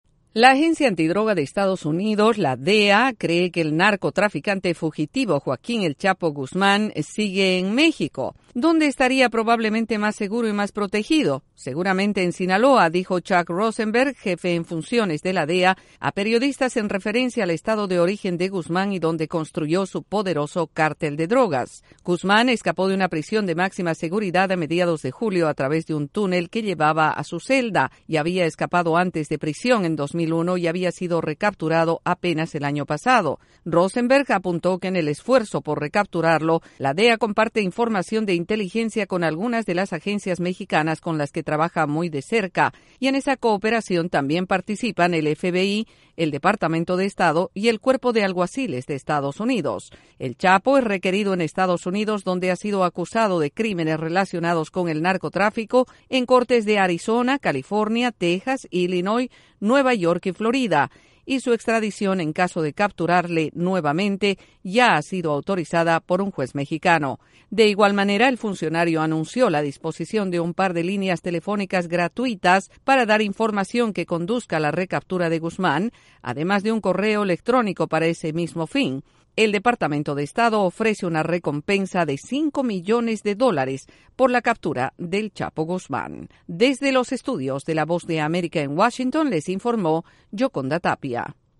La Agencia Antidrogas de Estados Unidos, DEA, tiene sospechas sobre la permanencia del fugado narcotraficante Joaquín “El Chapo” Guzmán en México. Desde la Voz de América en Washington informe